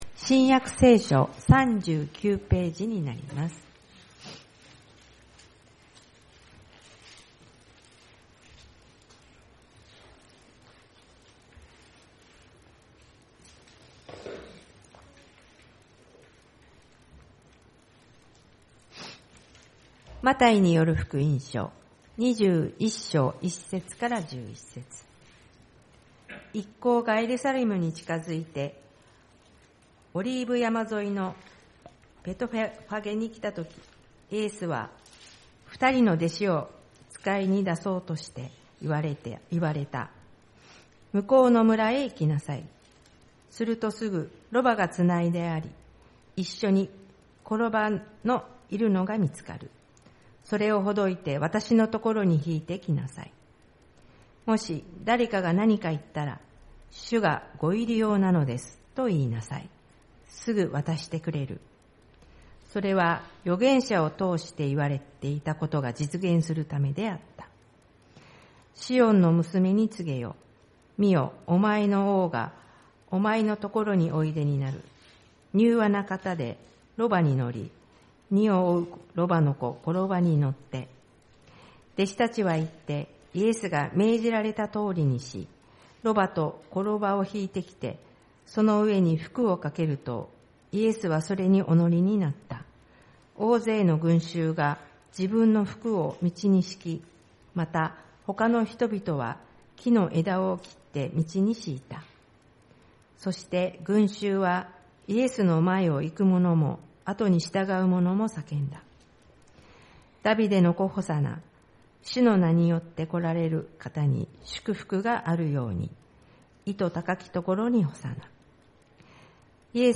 棕櫚主日礼拝「受難の主をわたしの王としてお迎えする
メッセージ